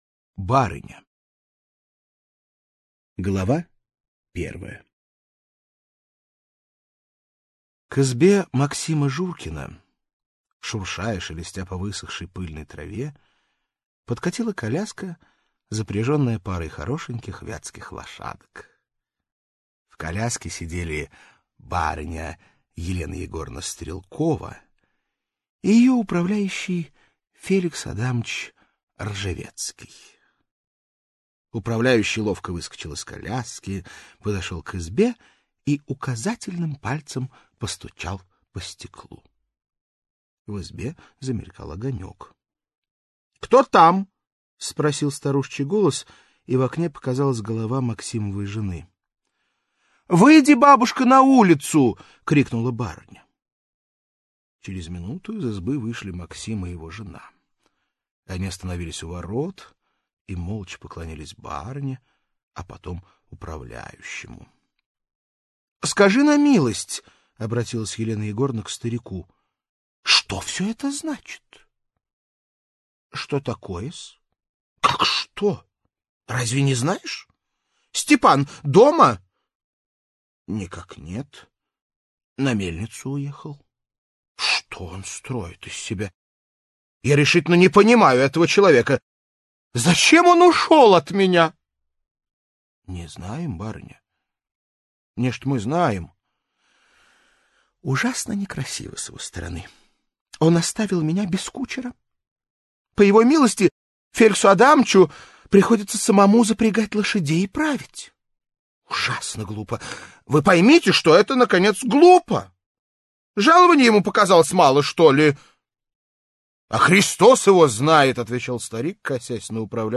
Аудиокнига «Цветы запоздалые» и другие рассказы | Библиотека аудиокниг
Прослушать и бесплатно скачать фрагмент аудиокниги